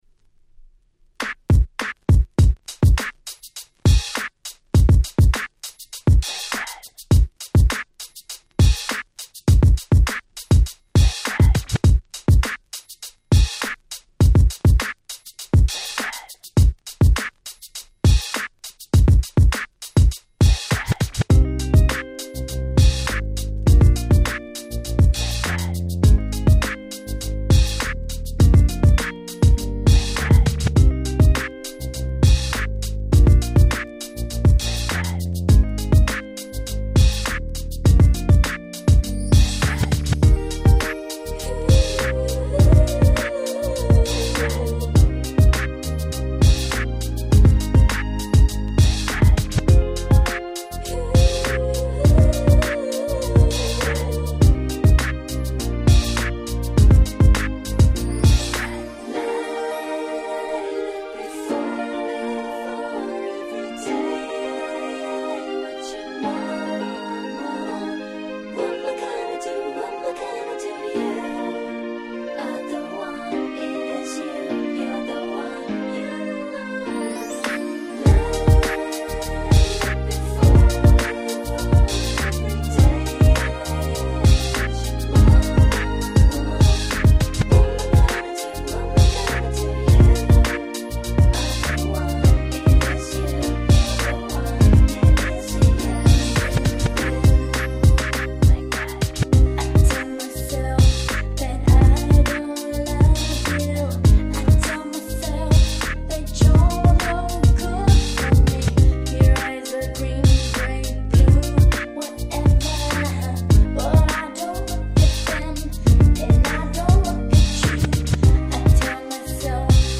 02' Nice R&B !!
キャッチーでPopで良質な1曲です。